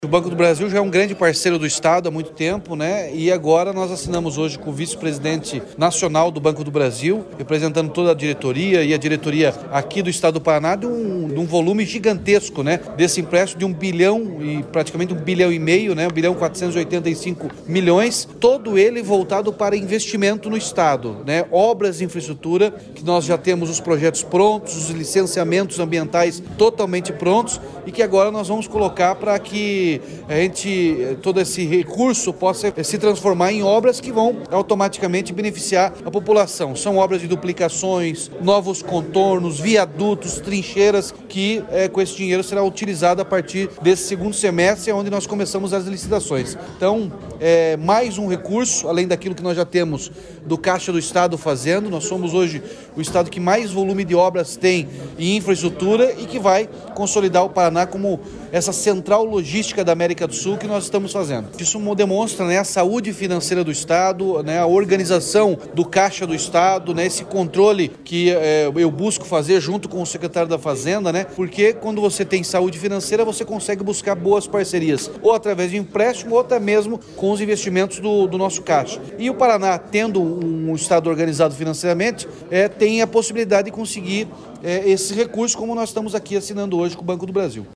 Sonora do governador Ratinho Junior sobre a assinatura de contrato de R$ 1,5 bilhão com o Banco do Brasil para financiar obras de infraestrutura